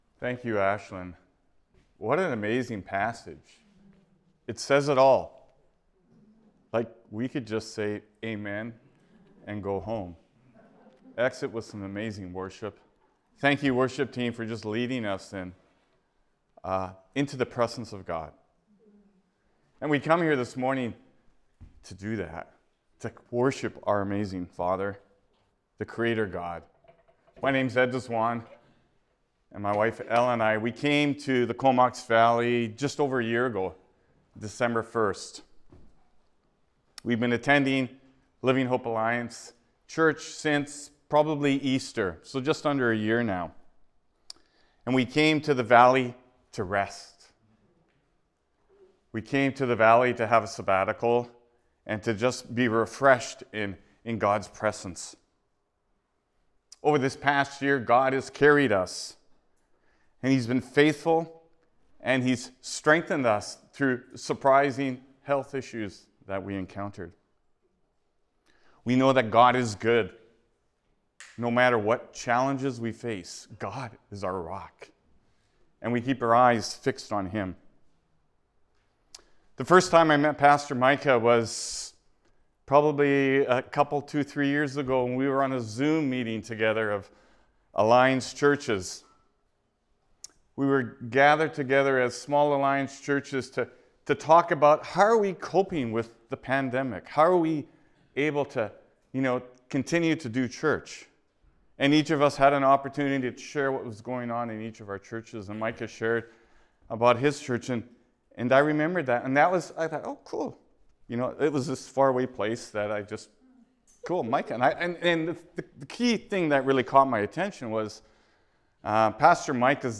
Sermons | Living Hope Church